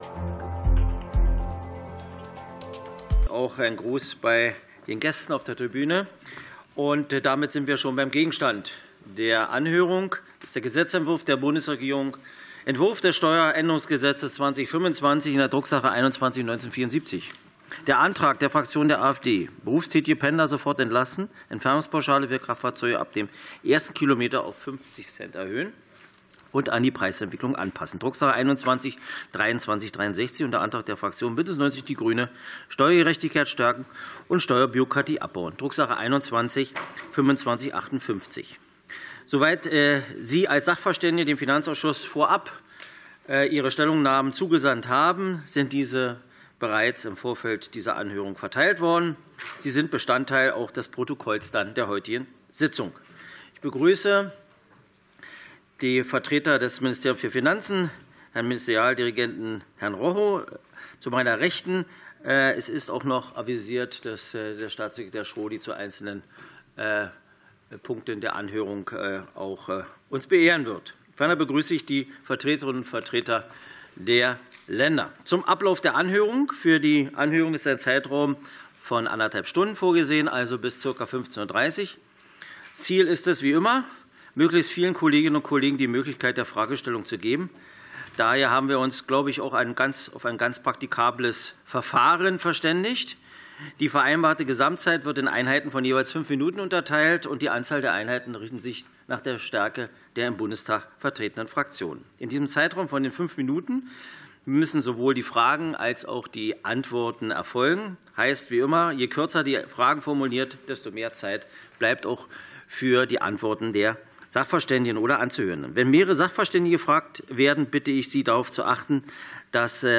Anhörung des Finanzausschusses